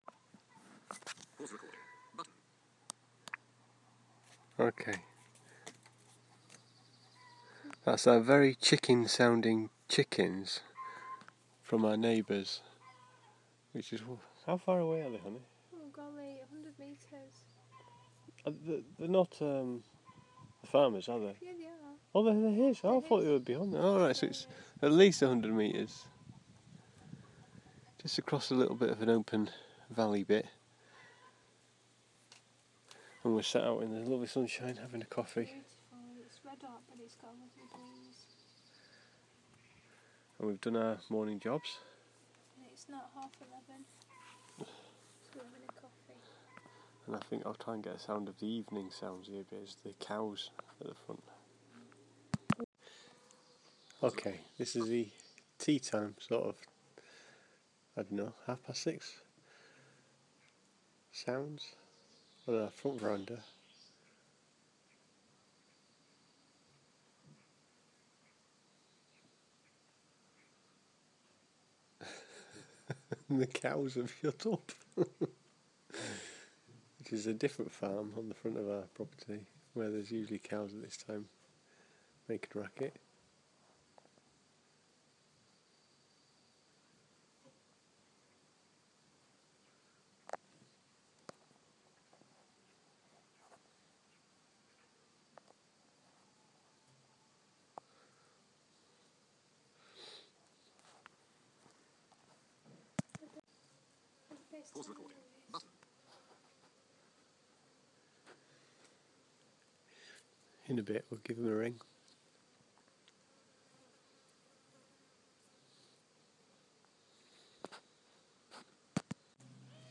Morning chickens and other outdoor sounds